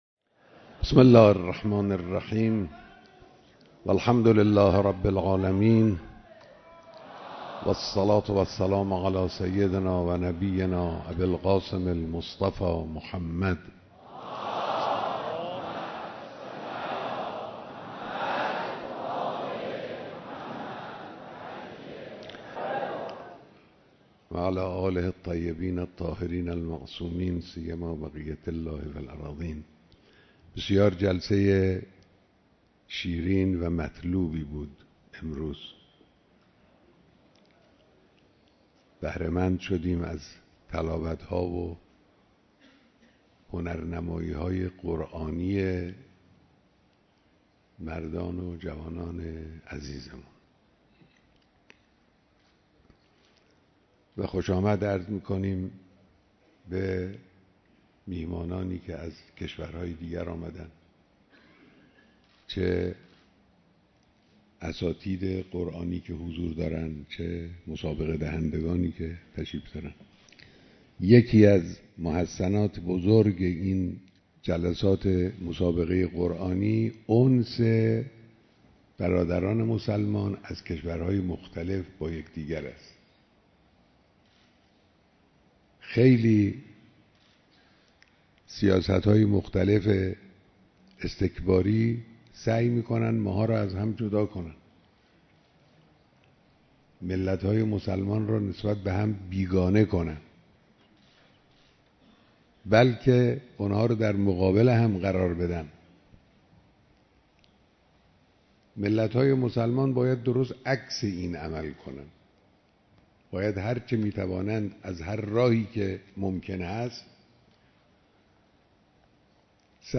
بیانات در دیدار قاریان و حافظان برتر شرکت‌کننده‌ در مسابقات بین‌المللی قرآن کریم